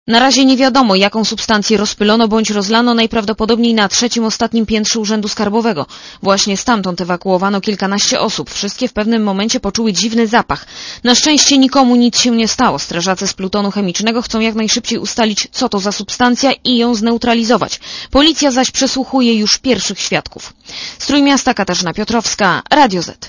Komentarz audio (92Kb)